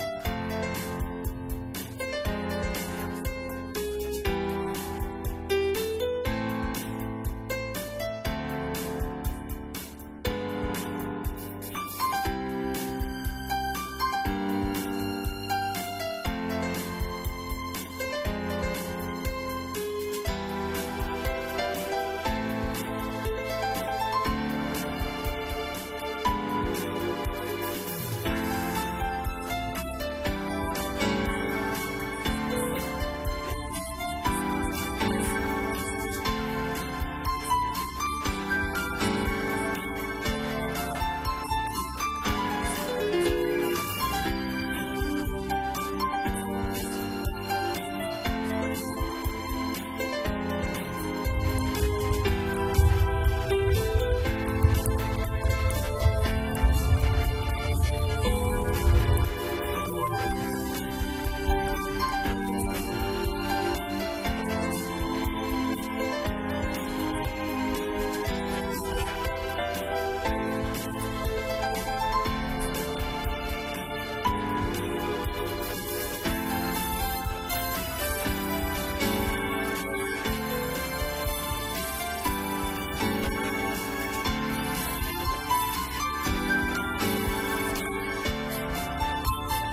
動画作成アプリにバンドルされてそうな曲